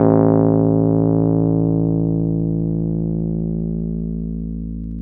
RHODES-A0.wav